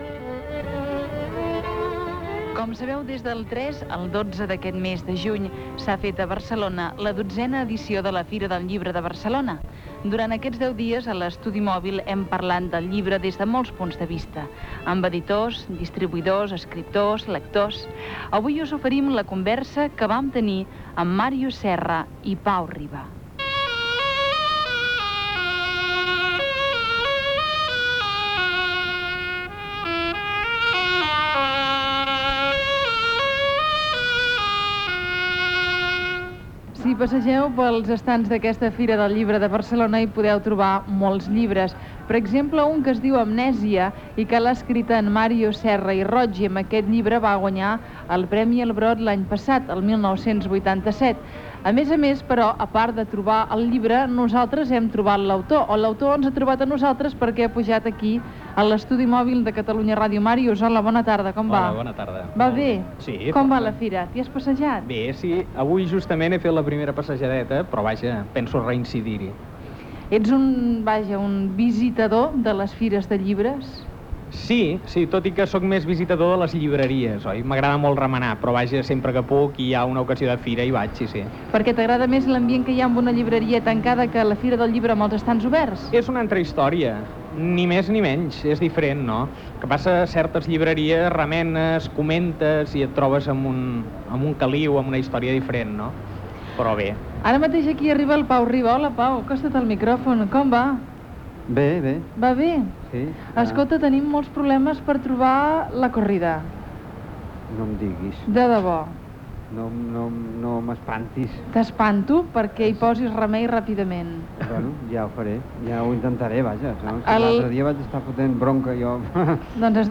Conversa amb l'escriptor Màrius Serra i el cantant Pau Riba a la XII Fira del Llibre de Barcelona